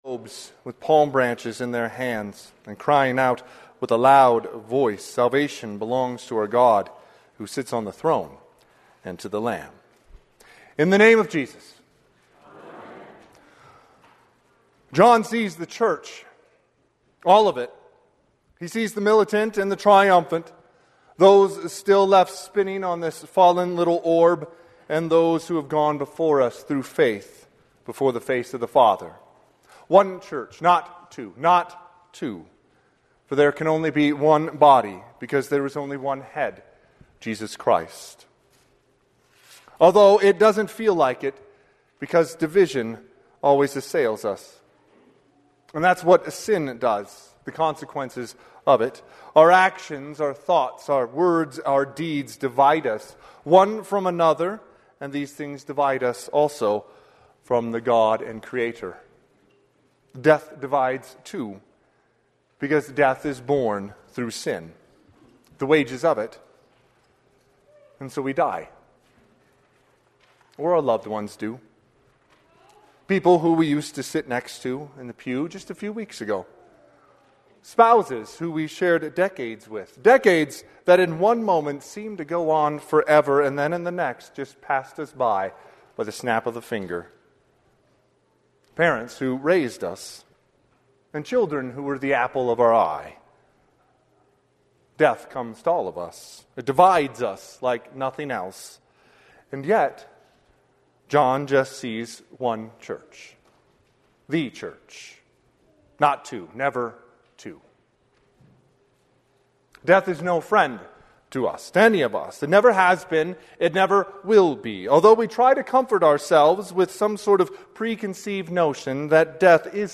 Sermon - 11/2/2025 - Wheat Ridge Evangelical Lutheran Church, Wheat Ridge, Colorado